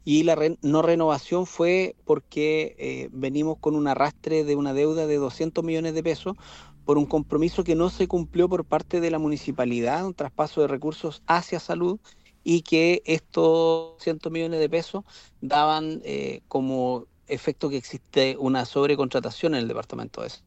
En conversación con La Radio, el alcalde de Paillaco, Cristian Navarrete, justificó el cese de funciones de los trabajadores aludiendo a una deuda arrastrada desde la administración anterior, que asciende a los 200 millones de pesos y que daban cuenta de una sobrecontratación en el Departamento de Salud Municipal.
cuna-paillaco-alcalde.mp3